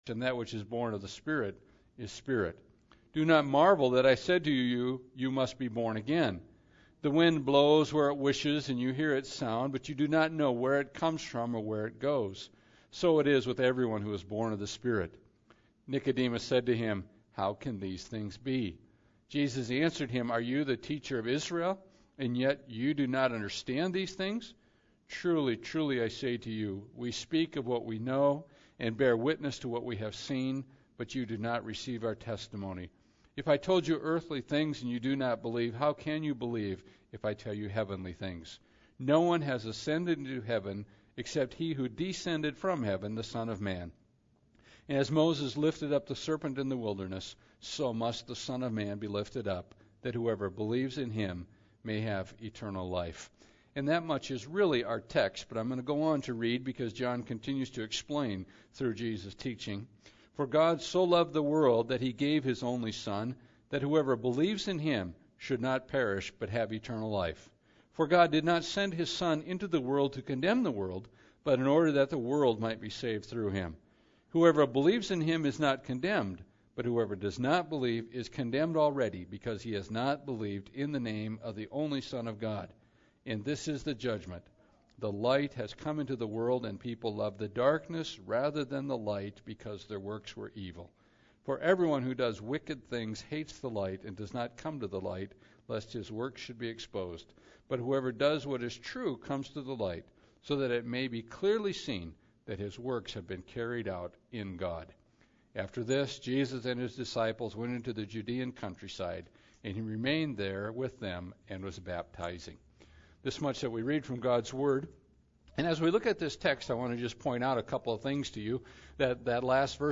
John 3 Service Type: Special Service Bible Text